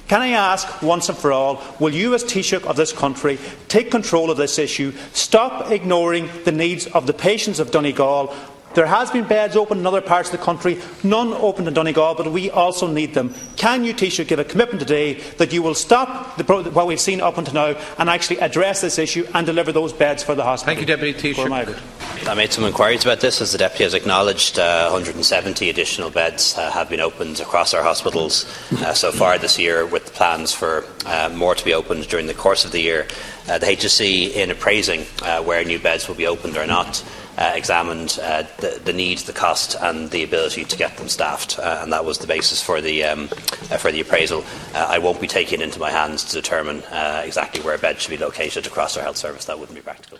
The Taoiseach says it is not practical for him to take responsibility for the process of determining where extra hospital beds should be located: